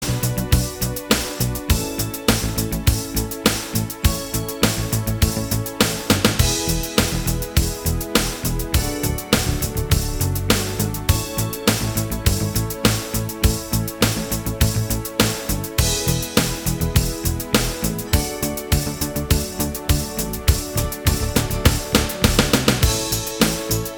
Minus All Guitars Pop (1980s) 4:03 Buy £1.50